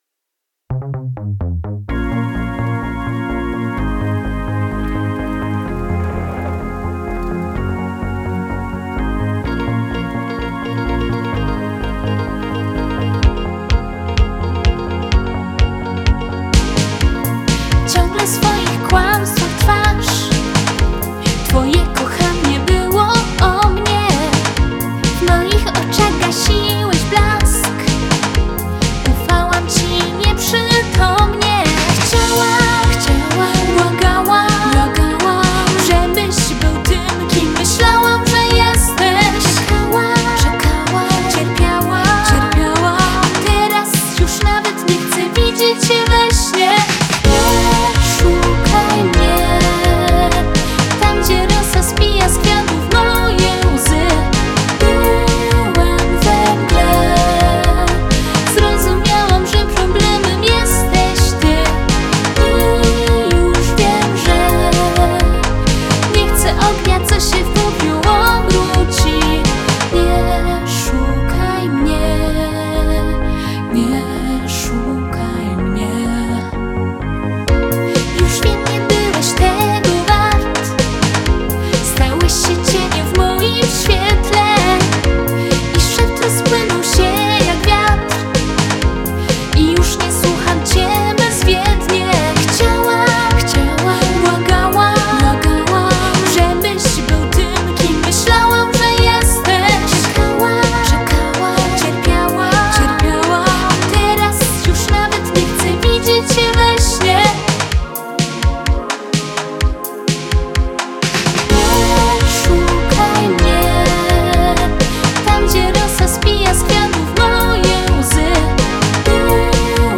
indie pop alternatywny pop lata 80 inspiracja